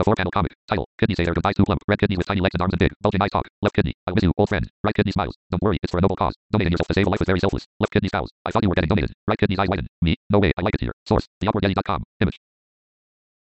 Screen readers do still pause for punctuation, but that pause is generally short and will get even shorter if the user increases the speech rate. This is how that comic was read out by my screen reader as an example and I actually slowed it down for this demonstration, I usually read faster than this.